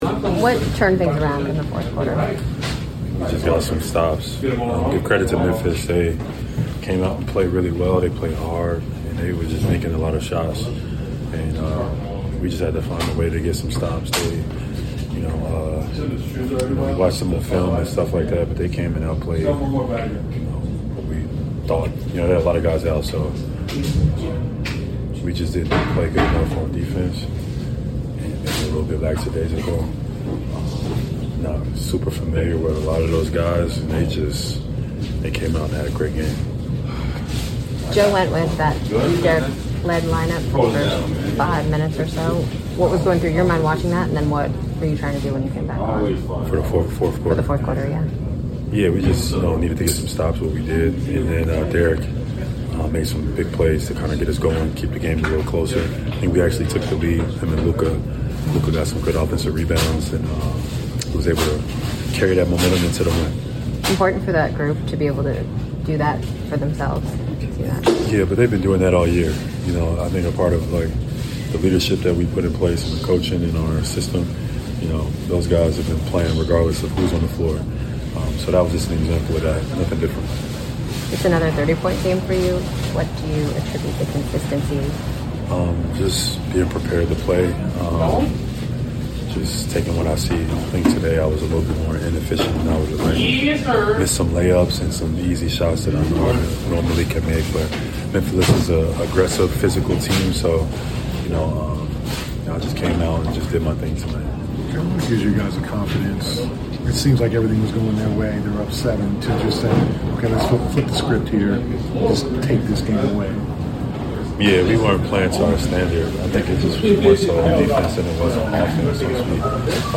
03-20-26 Boston Celtics Guard Jaylen Brown Postgame Interview
Boston Celtics Guard Jaylen Brown Postgame Interview after defeating the Memphis Grizzlies at State Farm Arena.